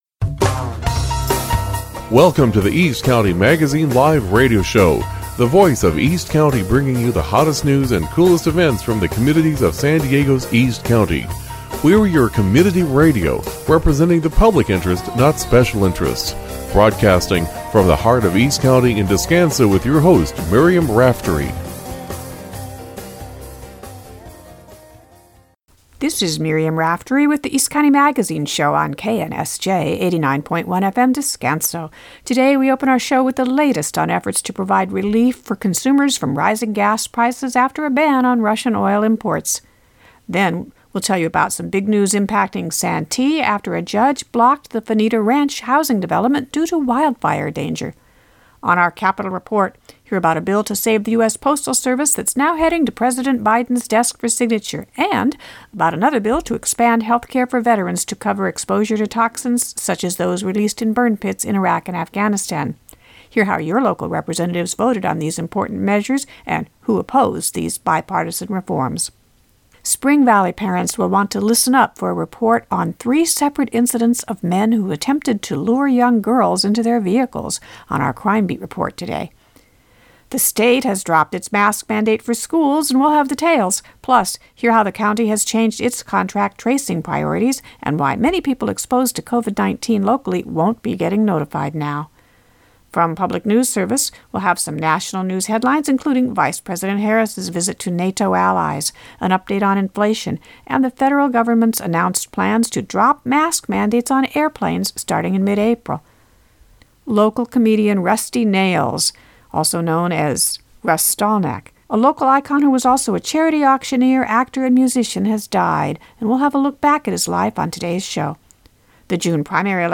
Shows also include station IDs and public service announcements.